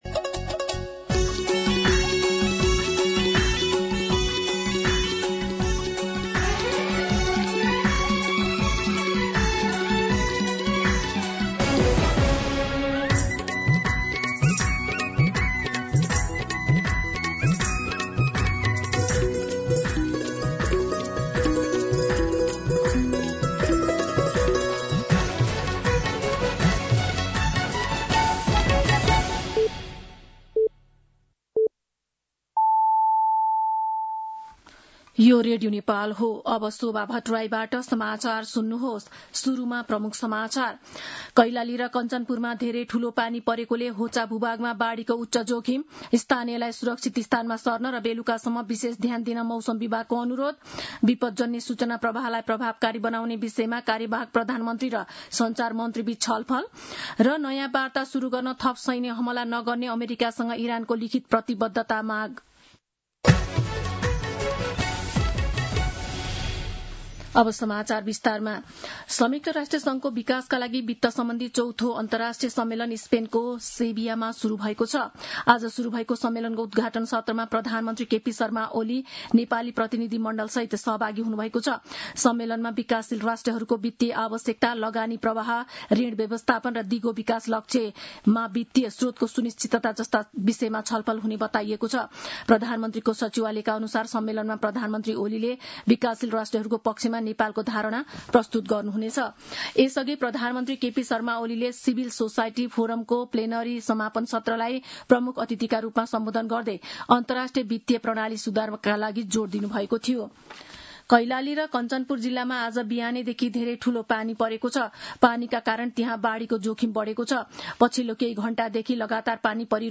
दिउँसो ३ बजेको नेपाली समाचार : १६ असार , २०८२